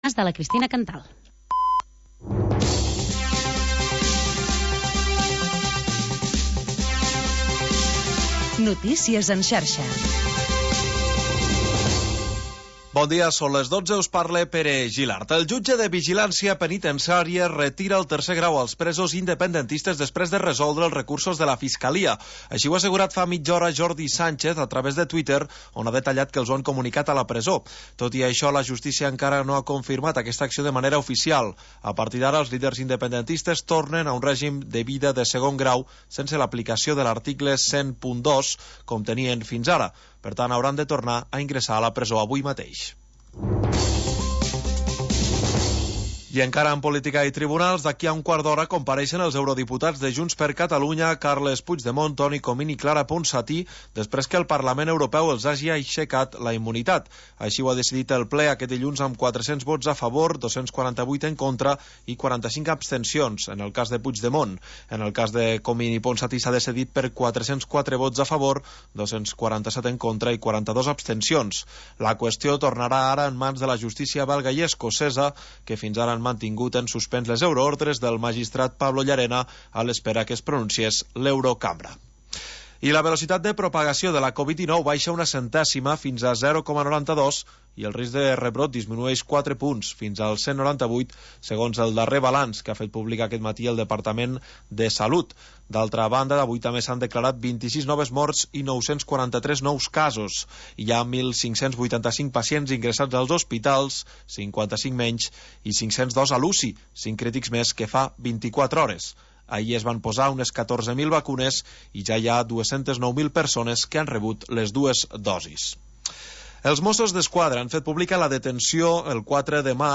Tercera hora musical del magazín local d'entreteniment